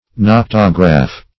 Search Result for " noctograph" : The Collaborative International Dictionary of English v.0.48: Noctograph \Noc"to*graph\, n. [L. nox, noctis, night + -graph.] 1.